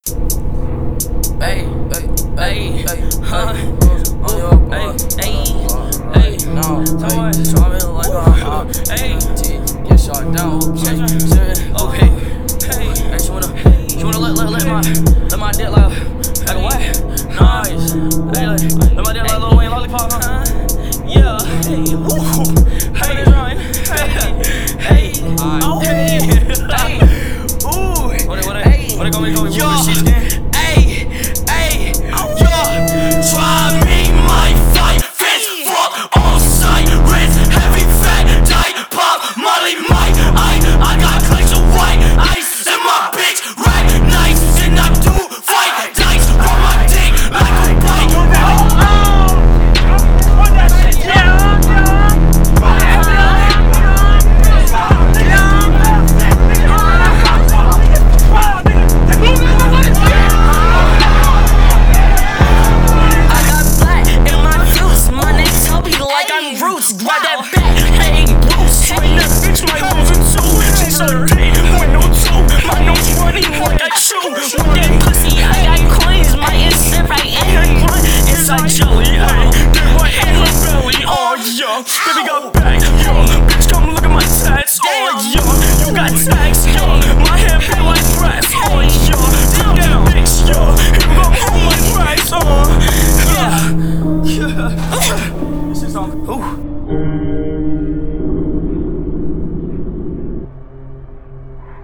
موزیک رپ